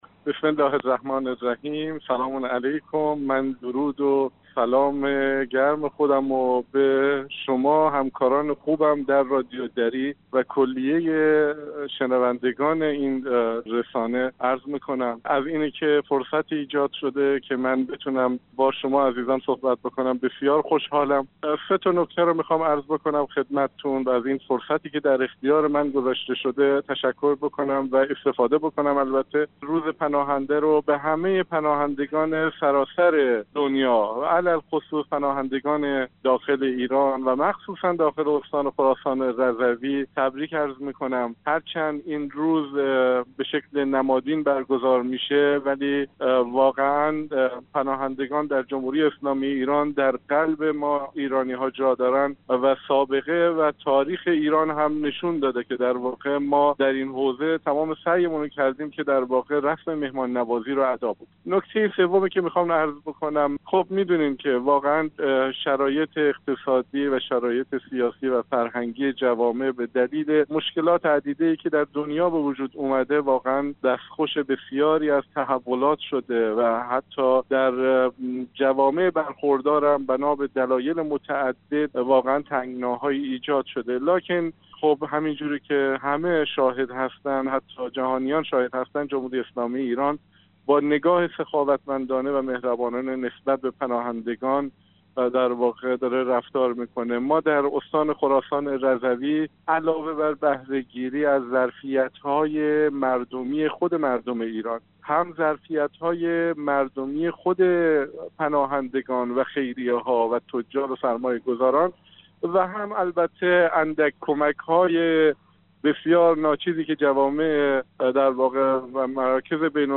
سید علی قاسمی همزمان با روز جهانی پناهنده در گفت و گو با برنامه انعکاس رادیو دری ضمن تبریک این روز به پناهندگان سراسر دنیا، به ویژه پناهندگان در داخل ایران و مخصوصا داخل خراسان رضوی اظهار داشت: اگرچه مراسم روز جهانی پناهنده به صورت نمادین برگزار می شود اما پناهندگان در جمهوری اسلامی ایران در قلب ایرانی ها جای دارند.